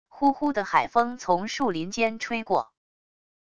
呼呼的海风从树林间吹过wav音频